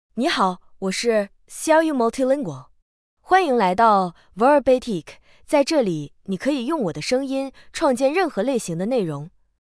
Xiaoyu Multilingual — Female Chinese (Mandarin, Simplified) AI Voice | TTS, Voice Cloning & Video | Verbatik AI
Xiaoyu Multilingual is a female AI voice for Chinese (Mandarin, Simplified).
Voice sample
Listen to Xiaoyu Multilingual's female Chinese voice.
Female